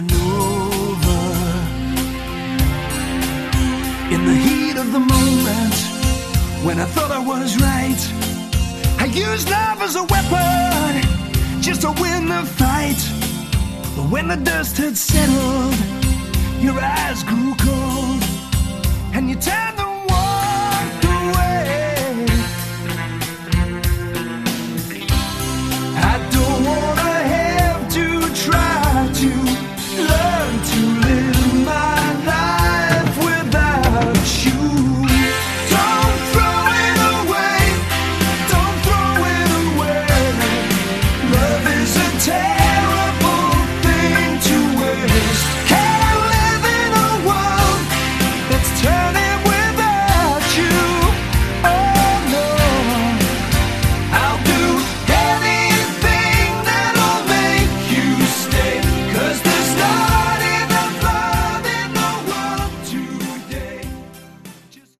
Category: Lite/West Coast AOR